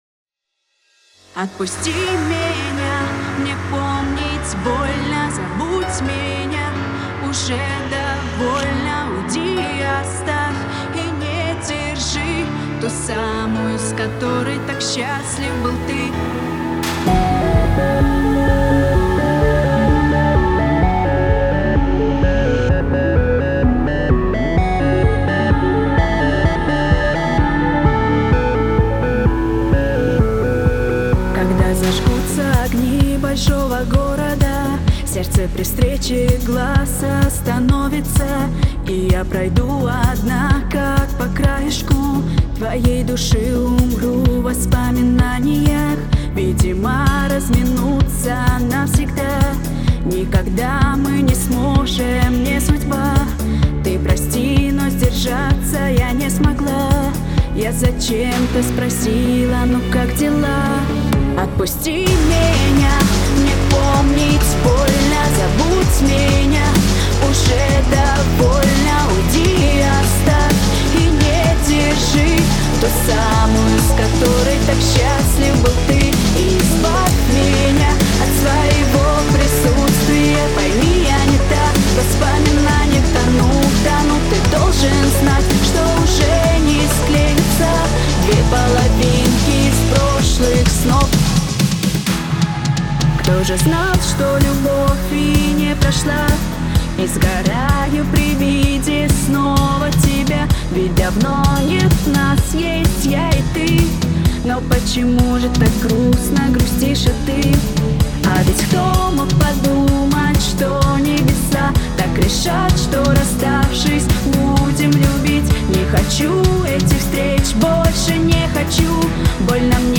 Natalie otpusti menya (pop, edm)
просьба указать проблемы микса, ибо уже запутался, особенно в вокале, сильно напрягает частота в районе верхней середины